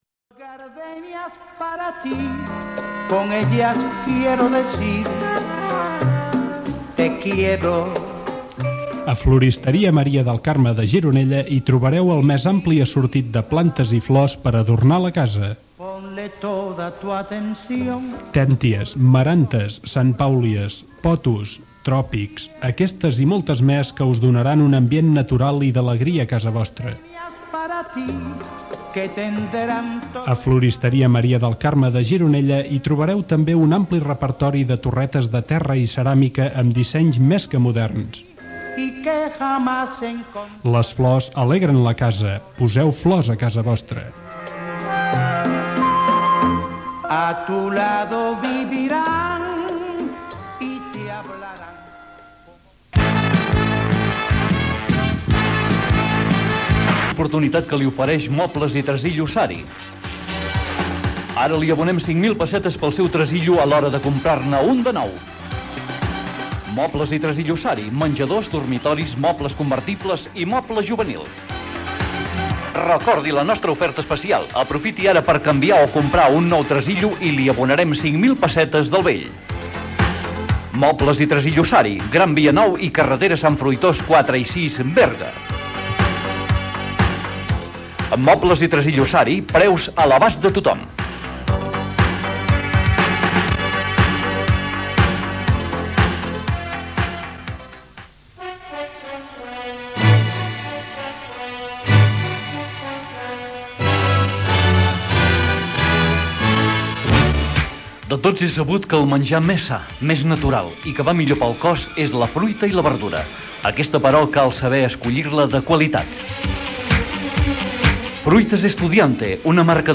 Presentador/a
FM